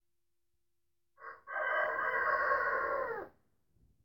Though his crow made him sound like he’d been smoking a pack a day for twenty years, he was a beautiful chicken.
Coppers-call.m4a